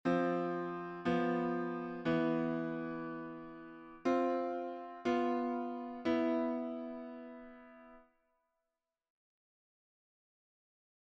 ガイド・トーン・ボイシングは、コードの3rdと7th（ガイド・トーン）の2音で構成されるシンプルなボイシングです。
Ⅱm7-Ⅴ7-ⅠΔケーデンス（Key C)の例はこちら。
ガイドトーン・ボイシング（Dm7-G7-CΔ)